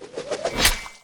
throw-hit-1.ogg